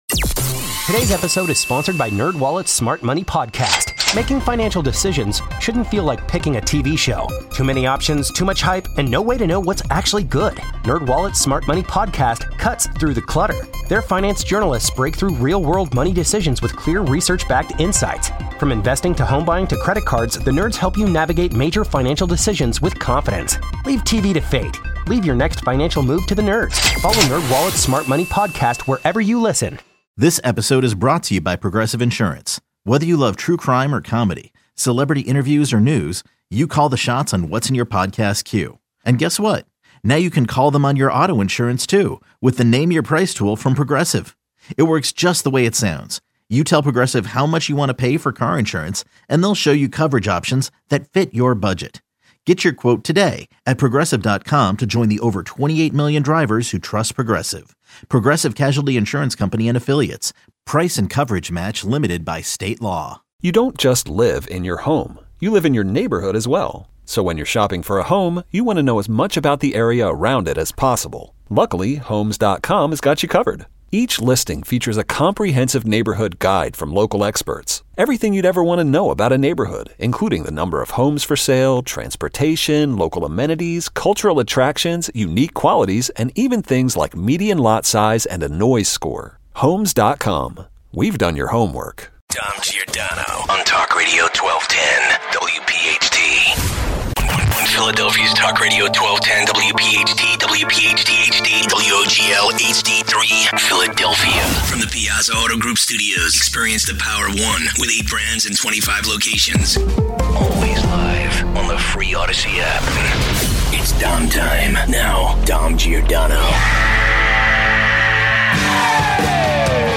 Is DEI about meritocracy? 1205 - Are these real Republicans speaking out against local leaders or bad actors? 1210 - Trump wants to introduce an immigration “Gold card”. Is that good idea? 1215 - Side - famous abbreviations/acronyms 1230 - Pennsylvania Senator Dave McCormick joins us today.